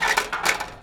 metal_rattle_spin_small_02.wav